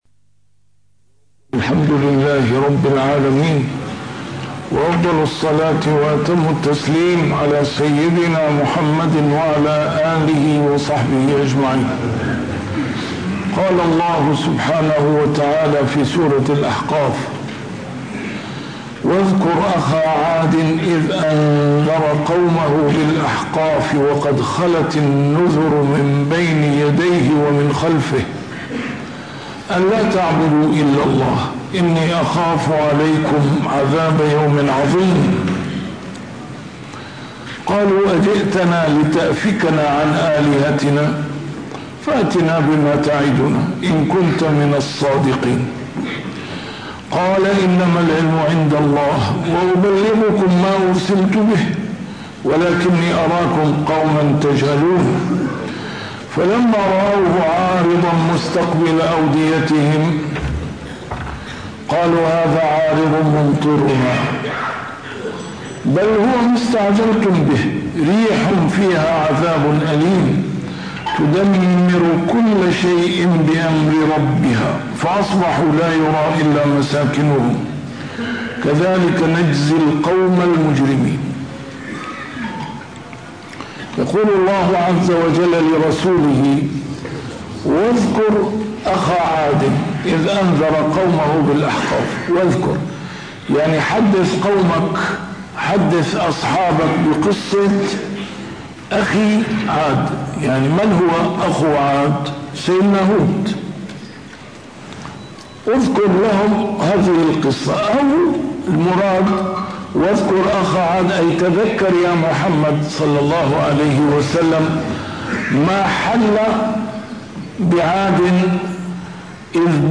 A MARTYR SCHOLAR: IMAM MUHAMMAD SAEED RAMADAN AL-BOUTI - الدروس العلمية - تفسير القرآن الكريم - تسجيل قديم - الدرس 626: الأحقاف 21-25